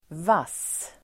Uttal: [vas:]